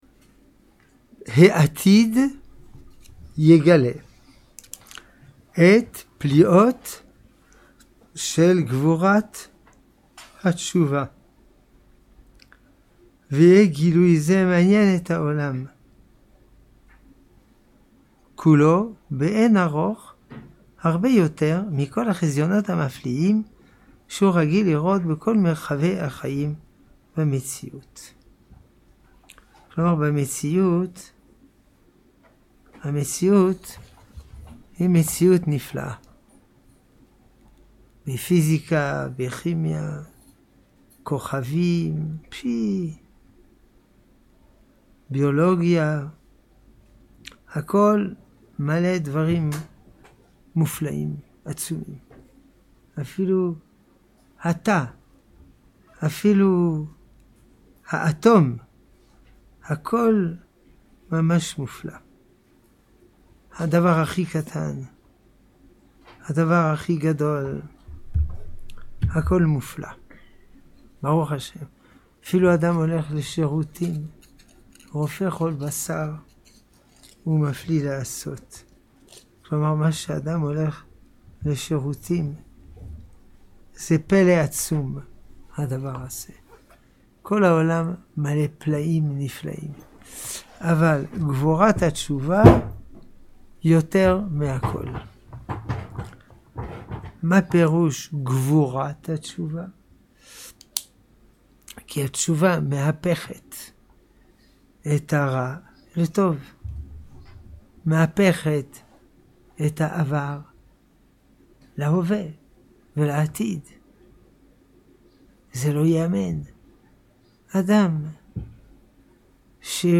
שיעורים באורות התשובה לרב קוק זצ"ל, בתוספת שאלות ותשובות בעניינים שונים